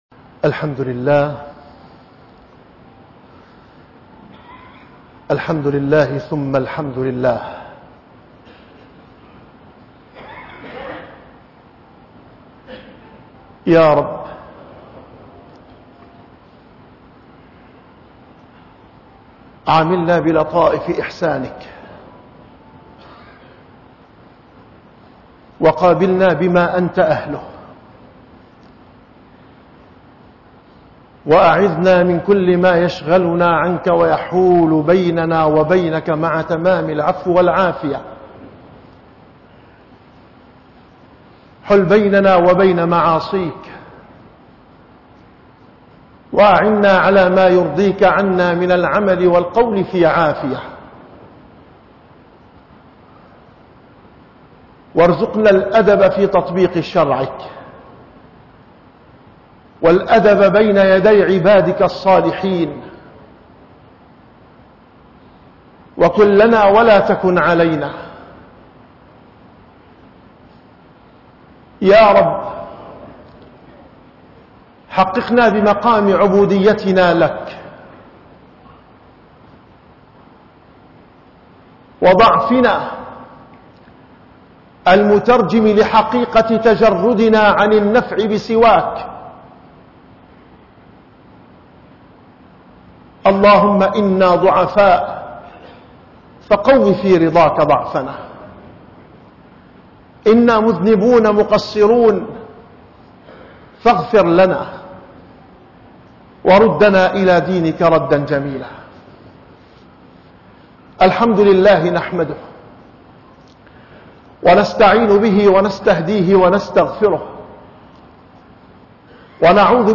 - الخطب -